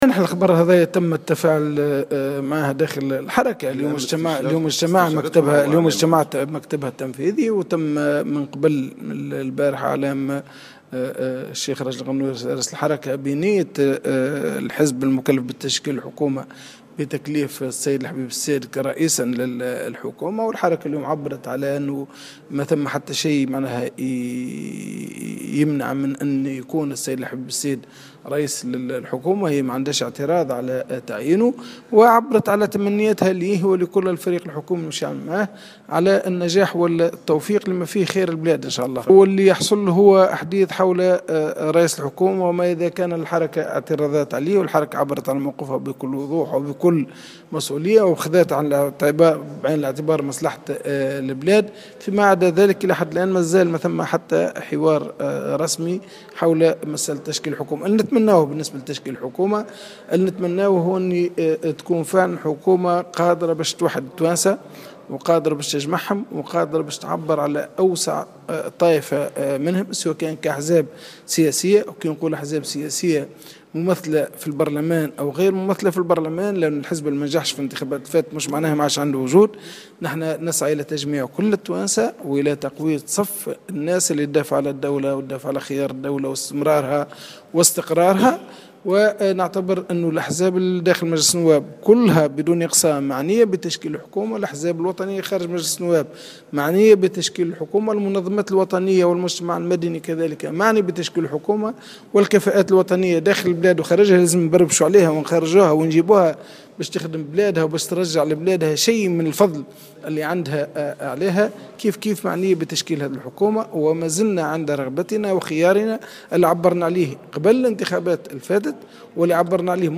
قال رئيس الكتلة النيابية لحركة النهضة،نور الدين البحيري في تصريح ل"جوهرة أف أم" خلال اجتماع للكتلة بالحمامات اليوم إن الحركة غير معترضة على تعيين الحبيب الصيد رئيسا للحكومة.